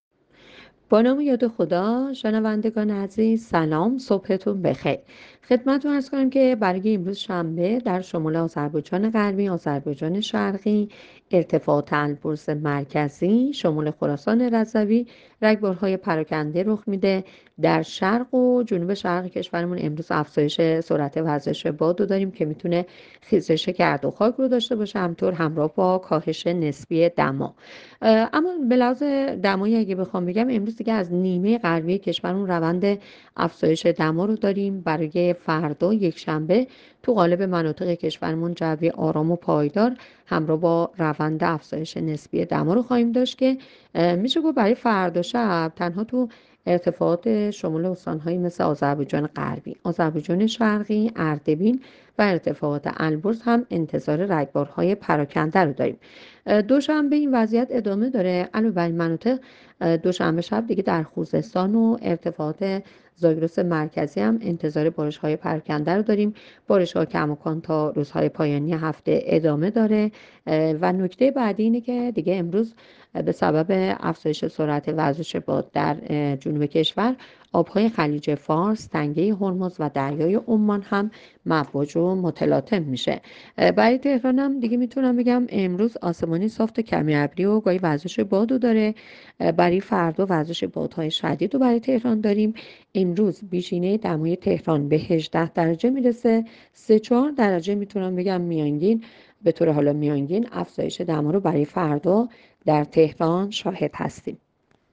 گزارش رادیو اینترنتی پایگاه‌ خبری از آخرین وضعیت آب‌وهوای ۳۰ فروردین؛